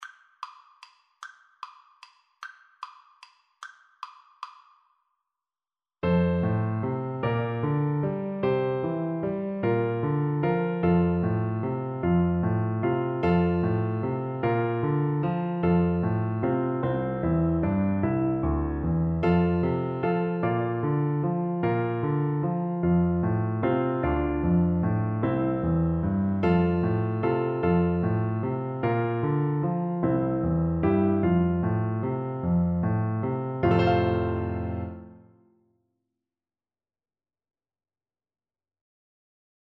Pop Trad. Morning has Broken (Bunessan) Clarinet version
Clarinet
F major (Sounding Pitch) G major (Clarinet in Bb) (View more F major Music for Clarinet )
3/4 (View more 3/4 Music)
= 150 Slow one in a bar
Traditional (View more Traditional Clarinet Music)